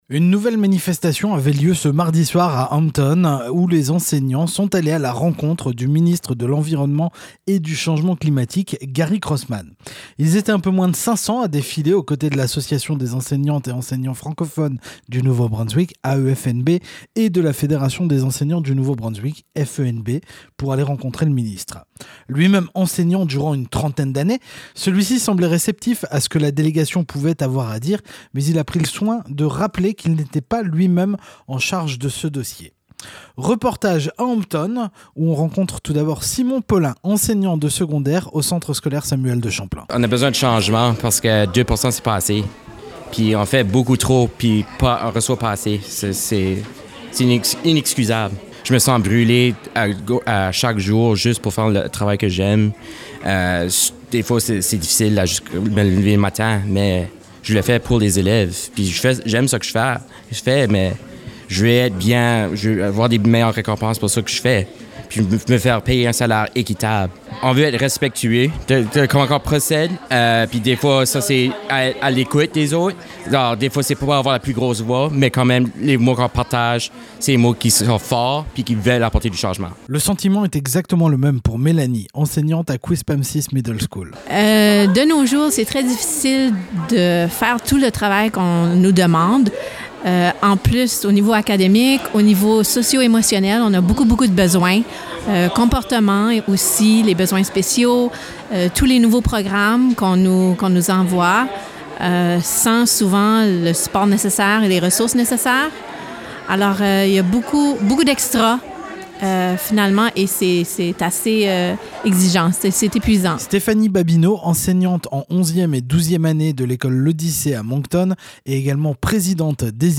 Le ministre Gary Crossman à pris la parole face à la foule.